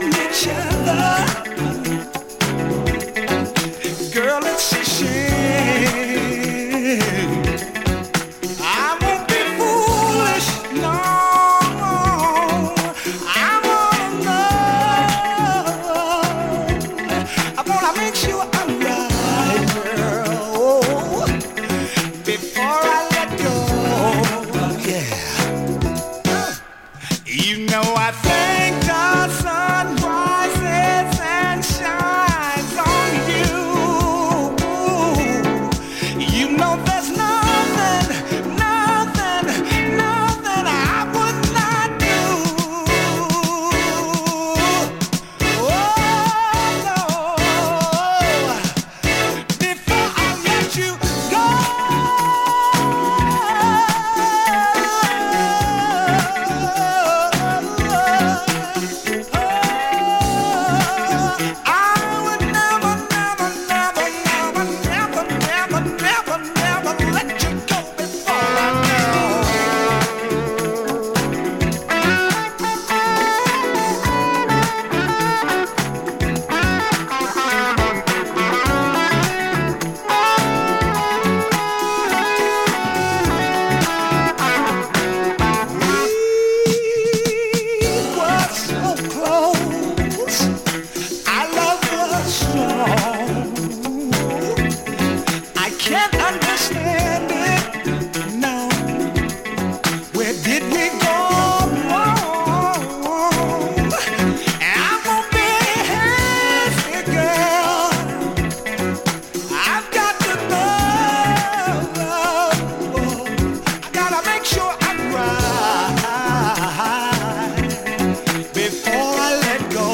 a new studio track